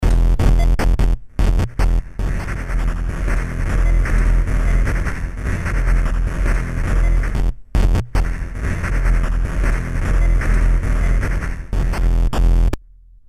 Digital delay with pitchshifting engine plus special tridimensional acoustic Roland RSS system.
atari 2600 processor
atari.mp3